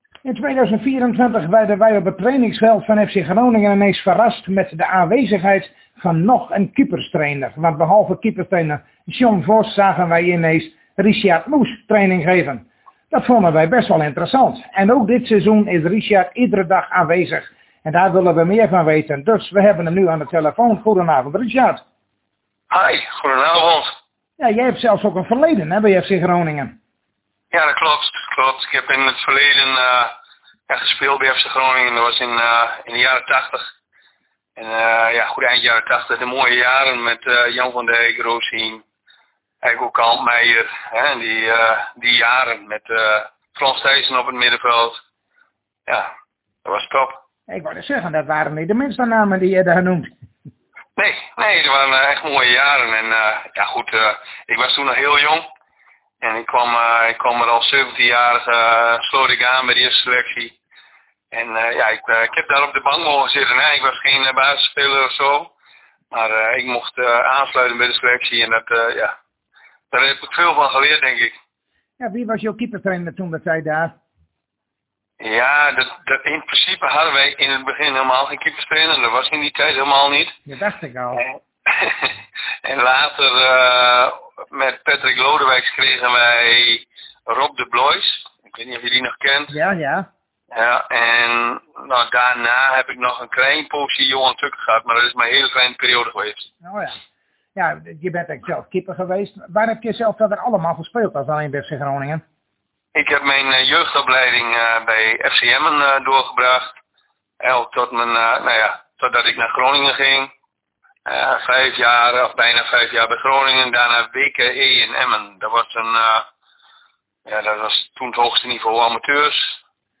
Uitgebreid interview